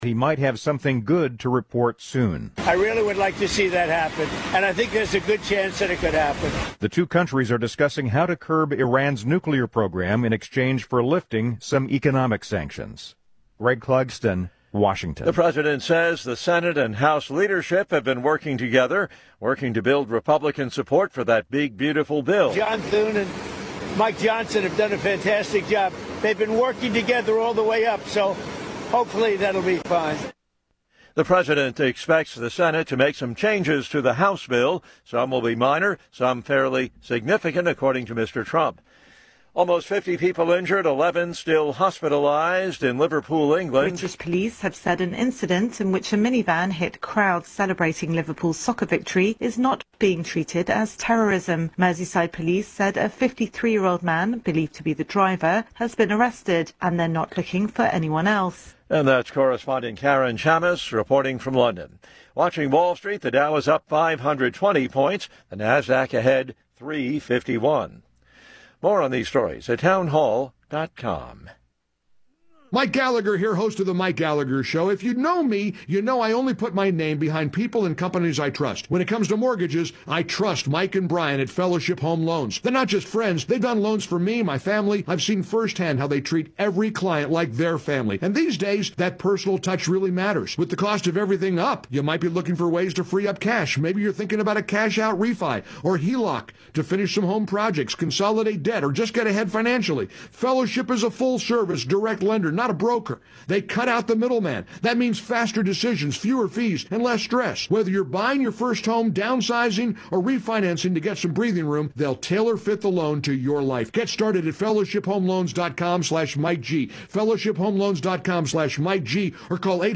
… continue reading 261 episodes # Daily News # Politics # News Talk # News # WCBM TALKRADIO AM 680